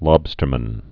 (lŏbstər-mən)